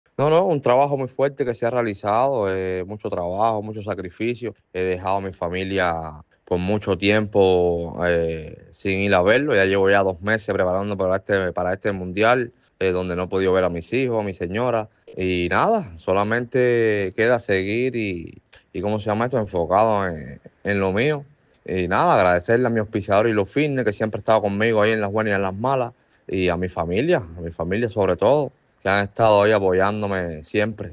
En conversación con ADN TOP, el levantador de pesas destacó el haber obtenido su quinta medalla en este tipo de eventos.